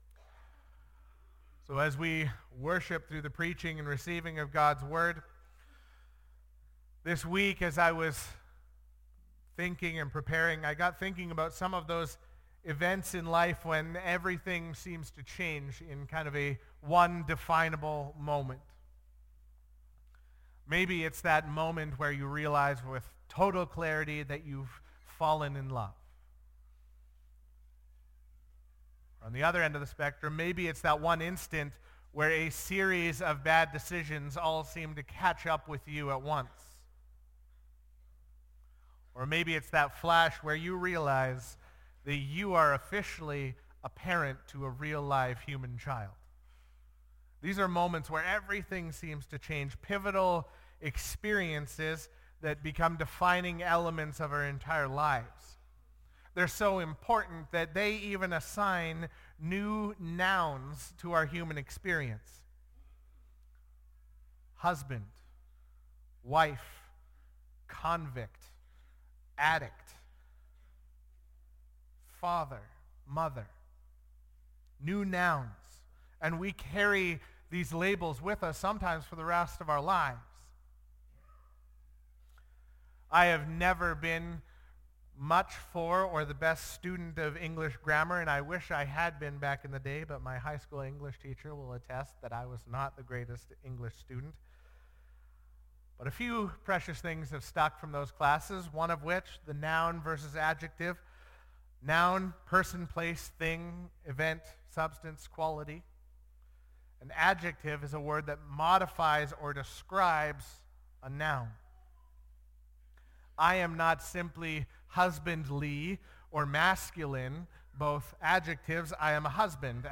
Sermons | Elk Point Baptist Church